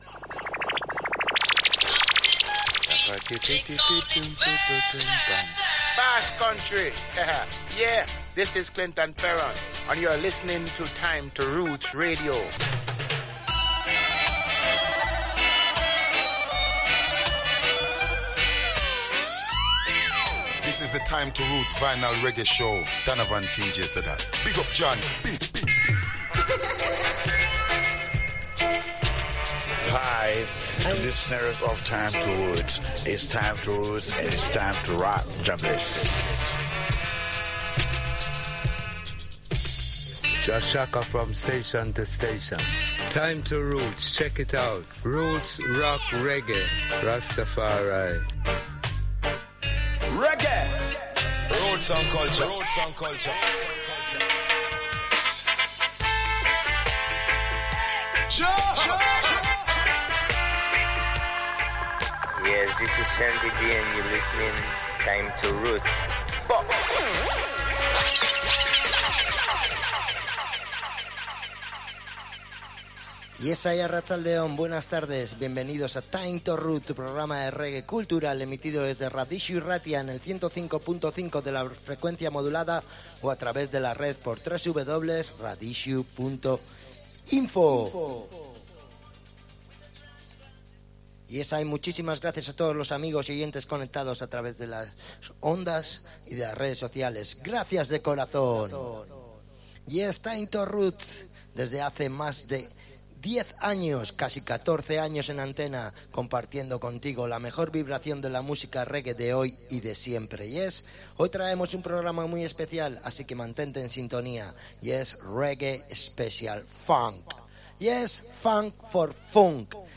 Thanks for being part of our dub and roots radio community!